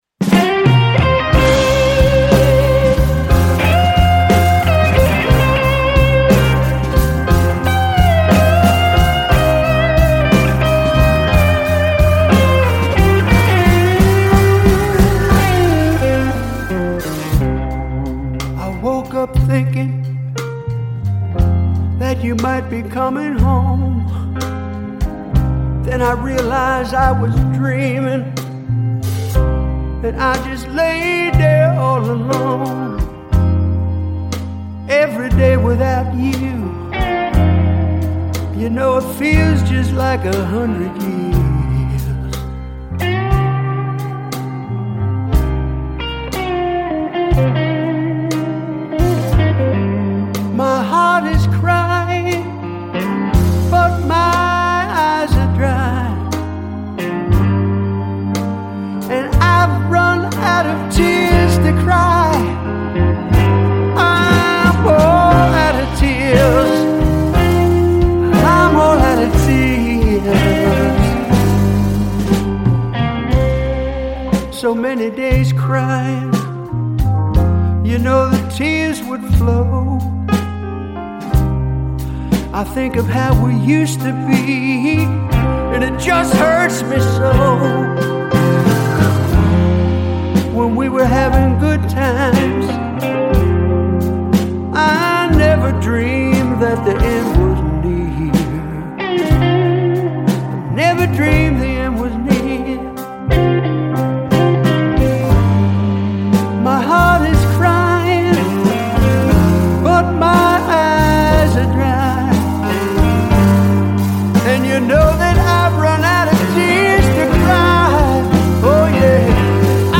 Жанр: Blues